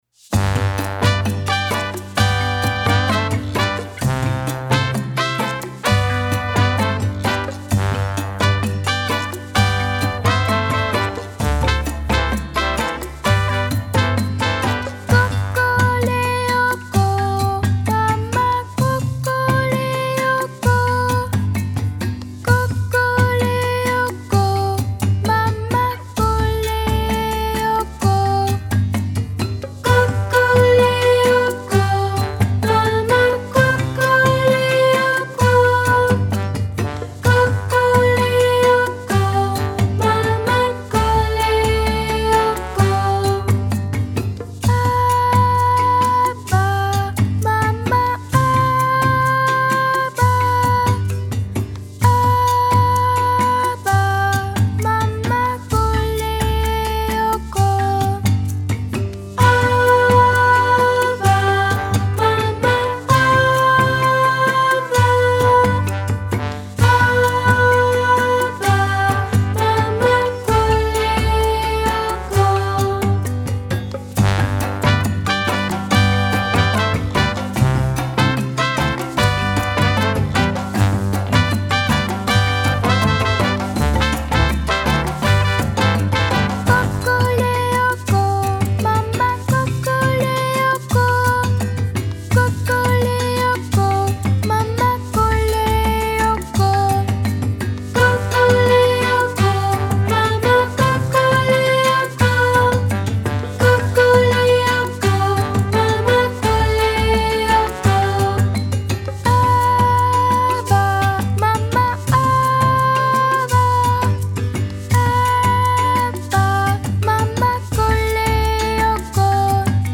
Exercici de percussió a Quart